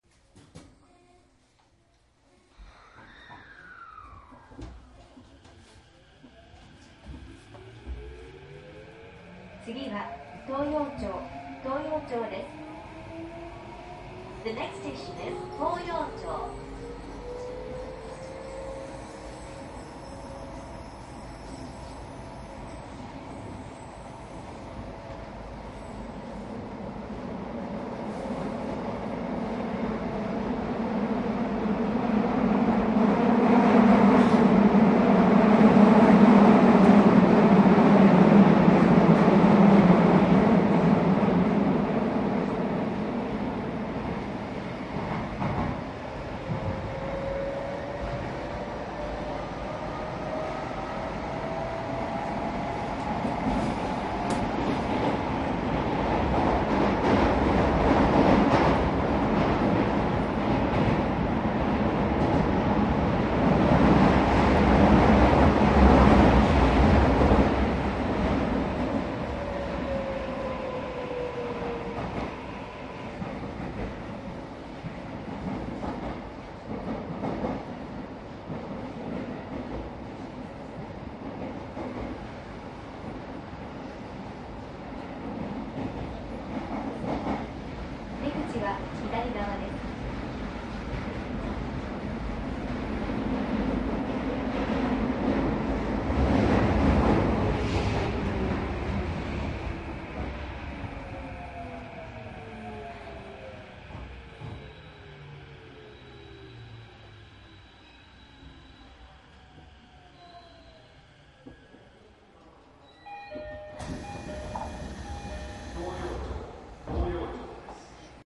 東京メトロ東西線 各駅停車走行音
東京メトロ東西線の各駅停車で中野方面を15000系にて録音しました。
マイクECM959です。MZRH1やDATの通常SPモードで録音。
実際に乗客が居る車内で録音しています。貸切ではありませんので乗客の会話やが全くないわけではありません。